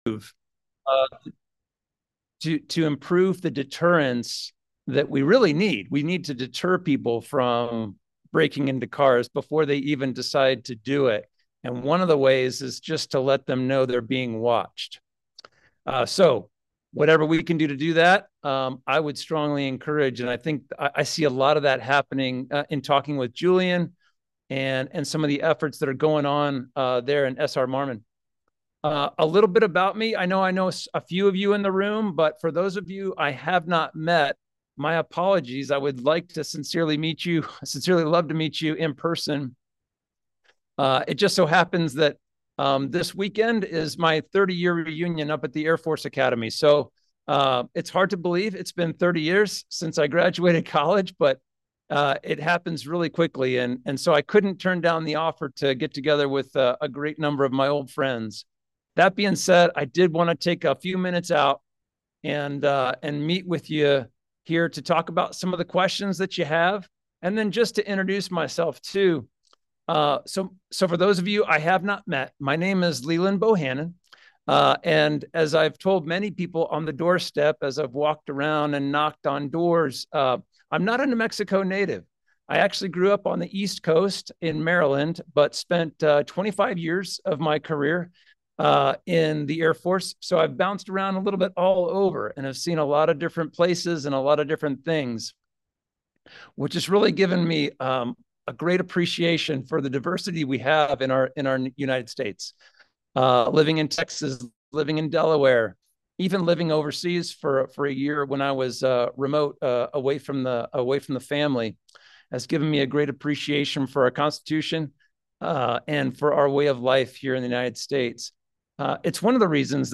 The file is unedited.